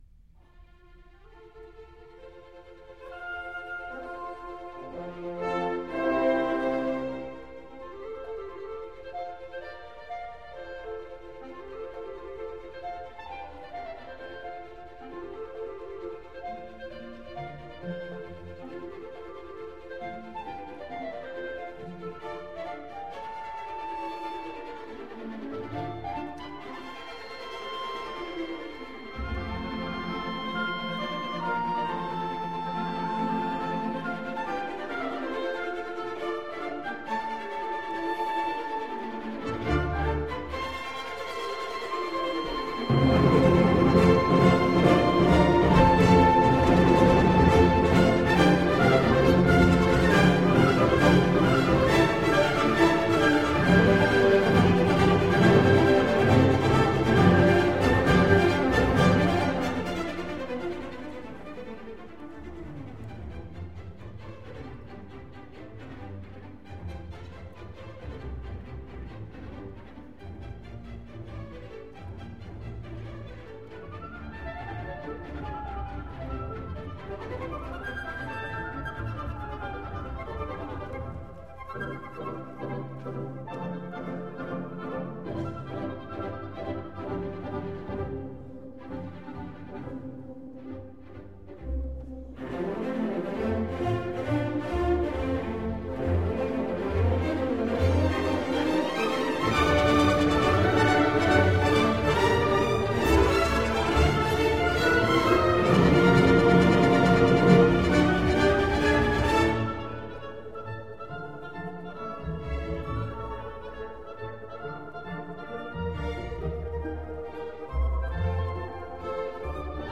不太快的急板